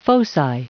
Prononciation du mot foci en anglais (fichier audio)
Prononciation du mot : foci